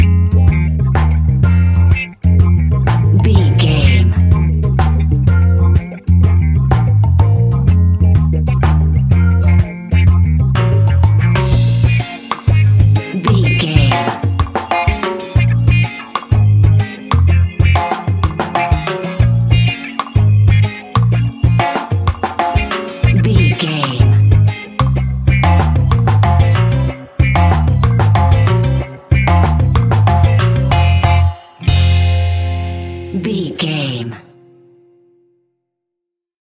Classic reggae music with that skank bounce reggae feeling.
Aeolian/Minor
dub
laid back
chilled
off beat
drums
skank guitar
hammond organ
percussion
horns